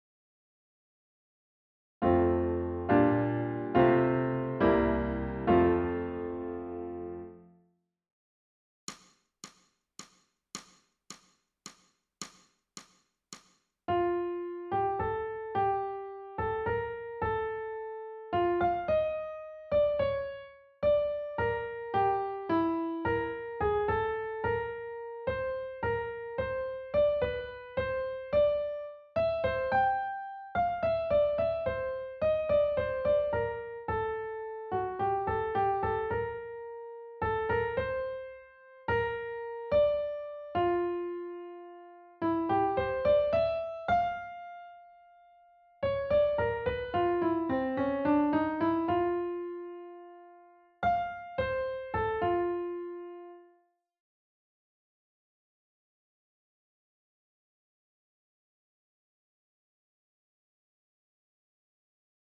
ソルフェージュ 聴音: 2-1-34